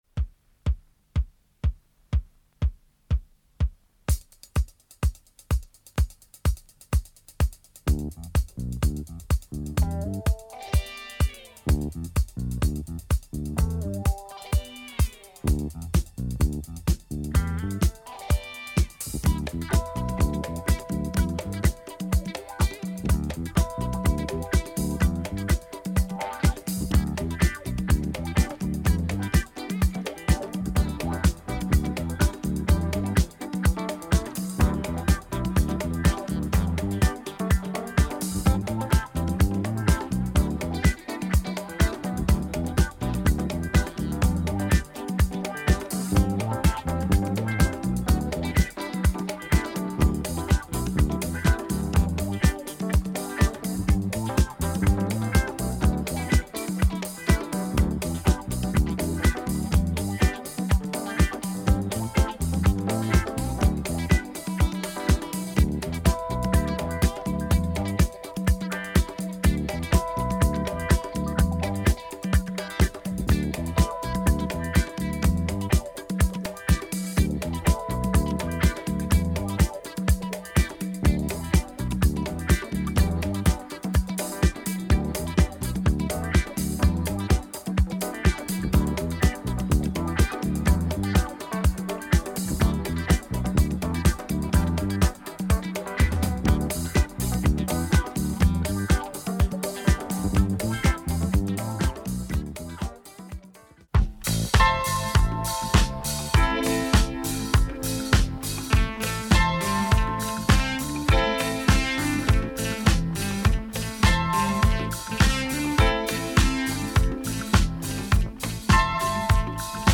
Killer French disco funk on this German library.